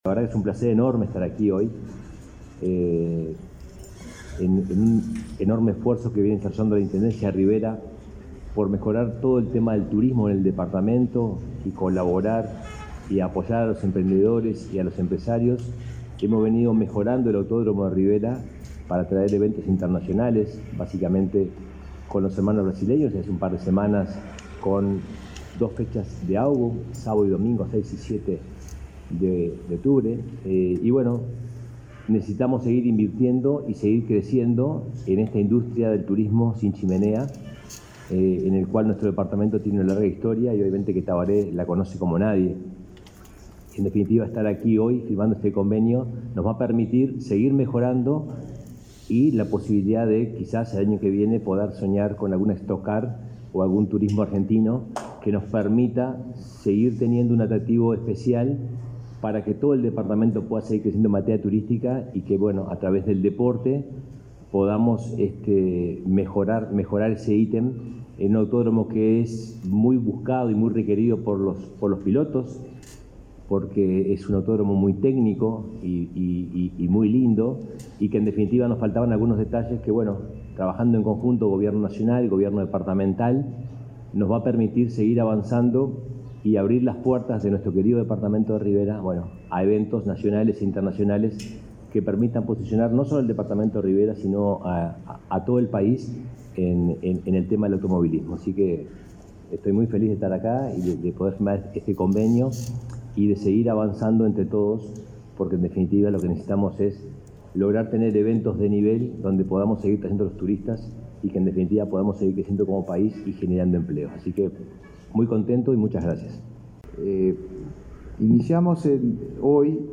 Palabras del intendente de Rivera y del ministro de Turismo